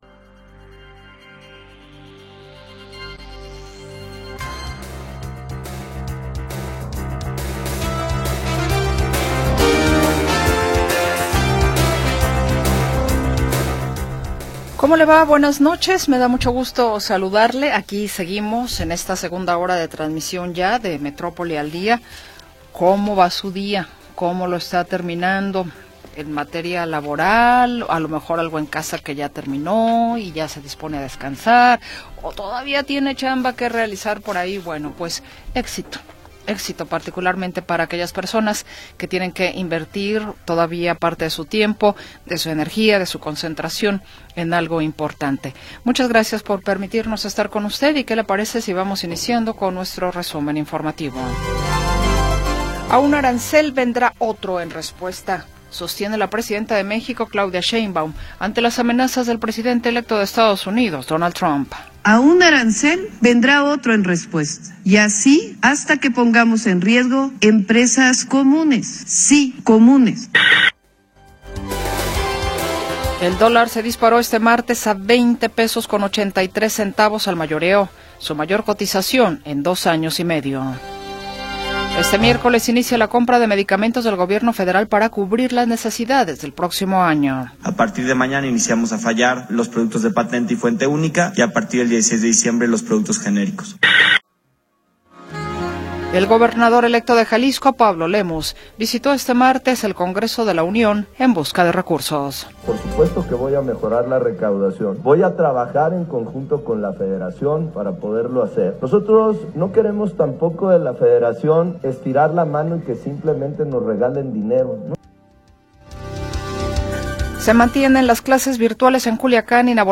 La historia de las últimas horas y la información del momento. Análisis, comentarios y entrevistas